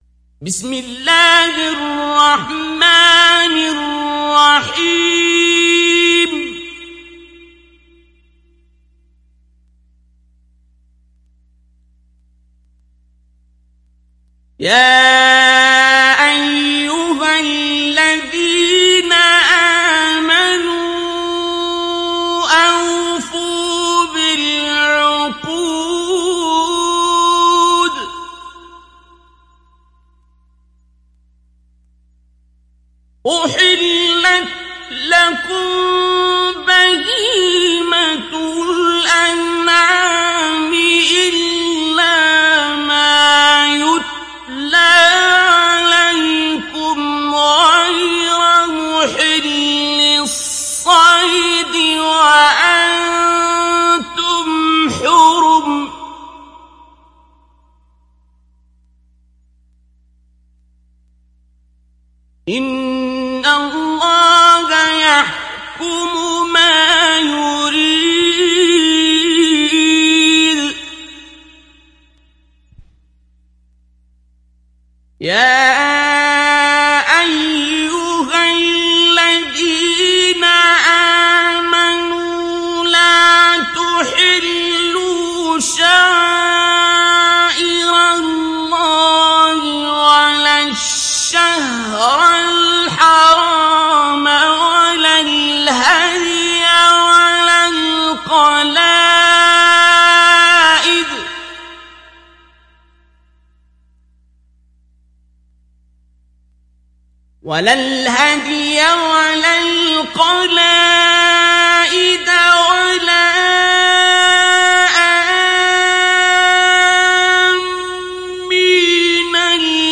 Surat Al Maidah mp3 Download Abdul Basit Abd Alsamad Mujawwad (Riwayat Hafs)
Download Surat Al Maidah Abdul Basit Abd Alsamad Mujawwad